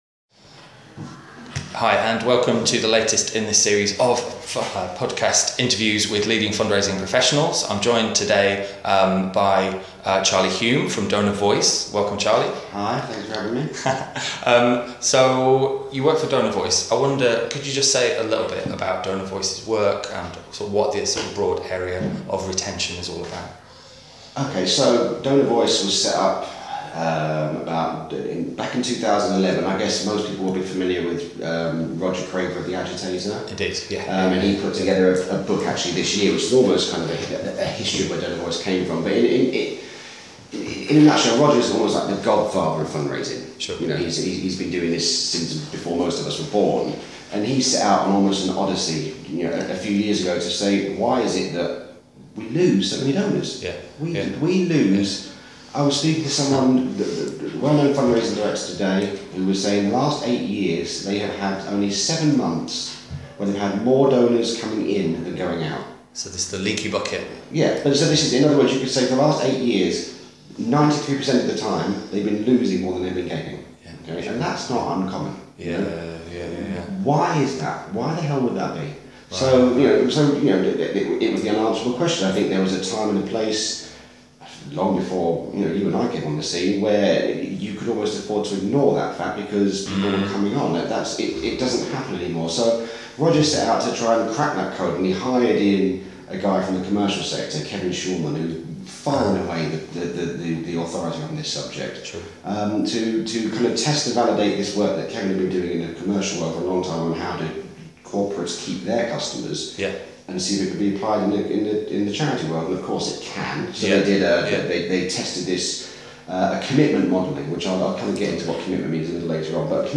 The interview is about retaining donors. Retention has the coming idea in fundraising for…a long time.